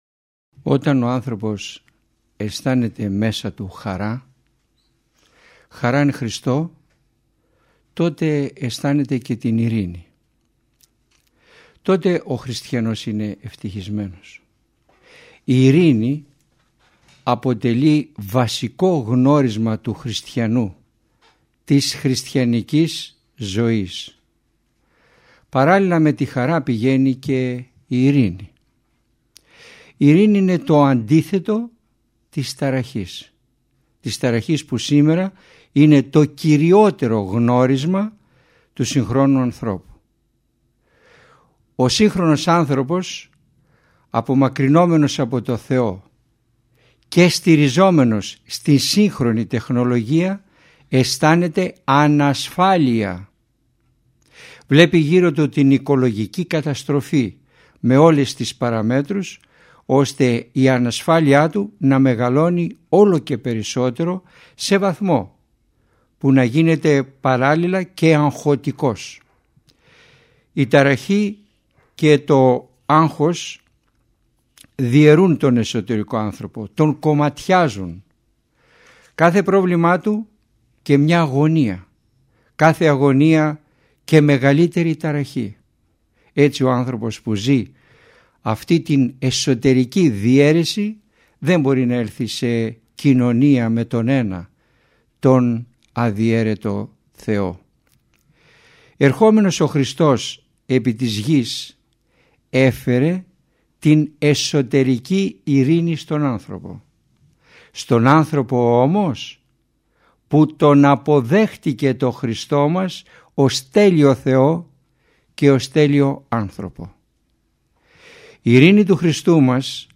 ηχογραφημένη ομιλία
Ελευθερία επιλογής Το μεγάλο πλεονέκτημα των ομιλιών Κάθε ομιλία είναι ένα ζωντανό κήρυγμα, όπου το παν εξαρτάται από τη θέληση του ακροατή˙ ο τόπος, ο χρόνος και ο τρόπος ακρόασης, το θέμα της ομιλίας εναπόκεινται στην προσωπική του επιλογή.